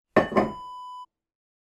Water Scoop Set Down Wav Sound Effect #3
Description: The sound of setting down a metal water scoop
Properties: 48.000 kHz 24-bit Stereo
A beep sound is embedded in the audio preview file but it is not present in the high resolution downloadable wav file.
water-scoop-set-down-preview-3.mp3